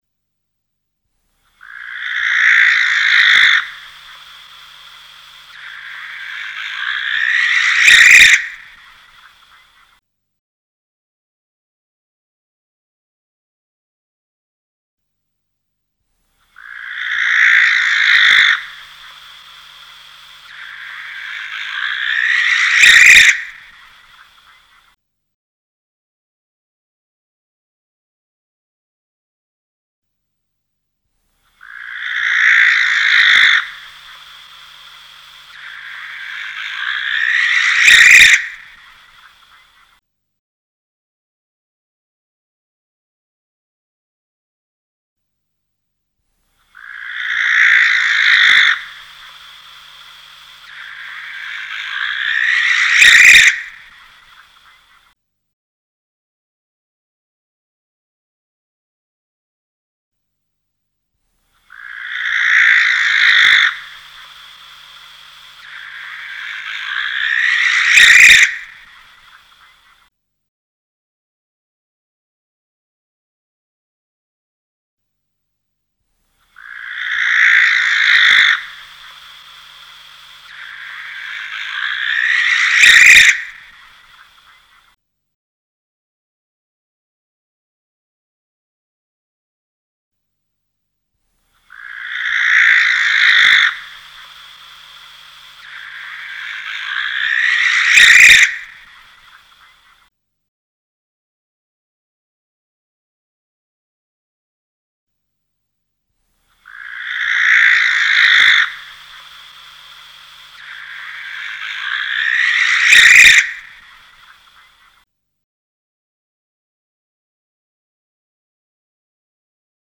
Playback_Tyto_alba
Playback_Tyto_alba.mp3